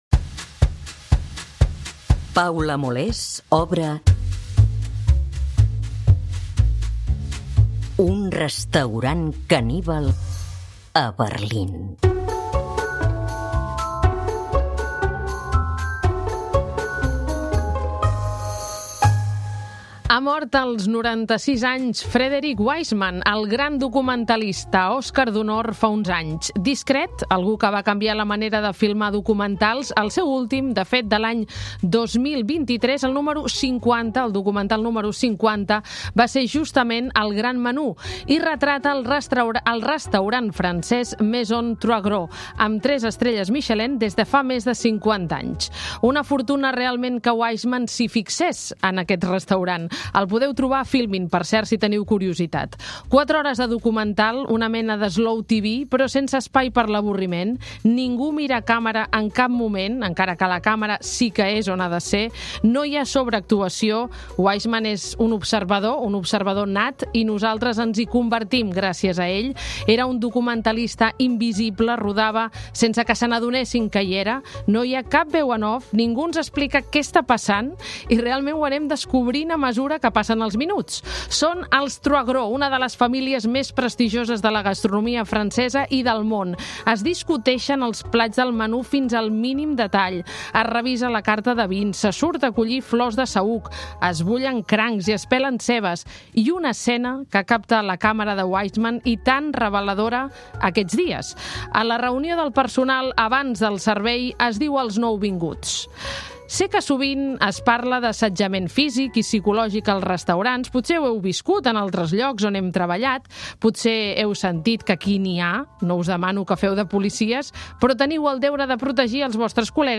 Celebrem l'Any Nou xinès des de la cuina del restaurant Franca de Barcelona. A la Xina, la taula és un element central de la festivitat i l'últim àpat de l'any ha d'incloure aliments com aviram, porc, peix i marisc.